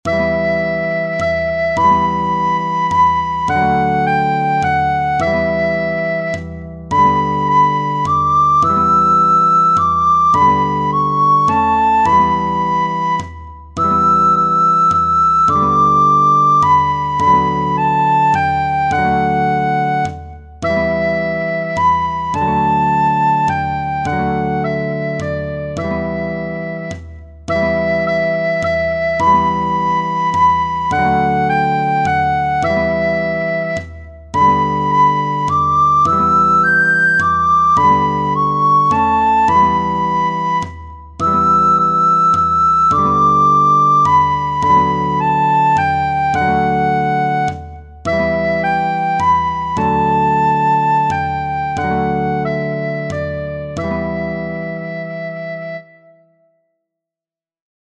In guitar line, all chords but one have glissando markings.
-Which of these options appear in the percussion accompaniment? Ostinato.
Scarborough Fair is a traditional British ballad whose lyrics can be traced back to a Scottish song from 1670.
In this score, a bodhran has been used.